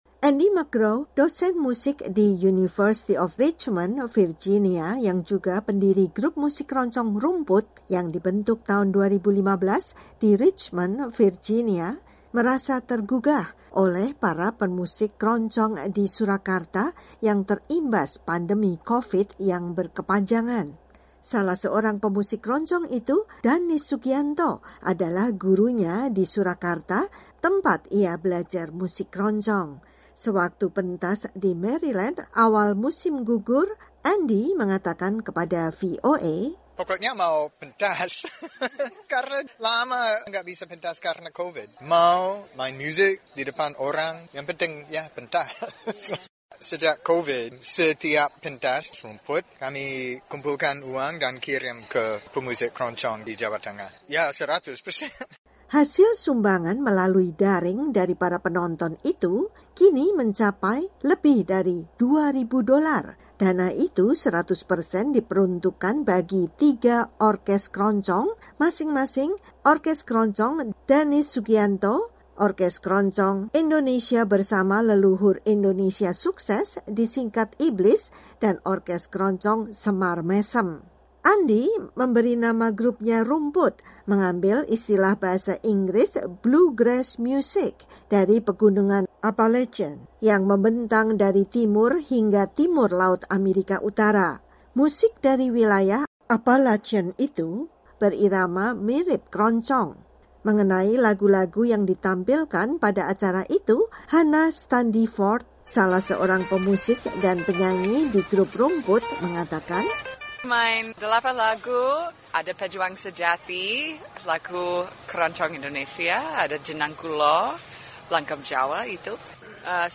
Pertunjukkan wayang beber karya seniman Surakarta yang diiringi lagu dan musik keroncong juga dihadirkan dalam acara di Maryland tersebut.